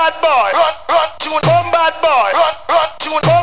TOP >Vinyl >Grime/Dub-Step/HipHop/Juke
Side-B1 / Vocal cuts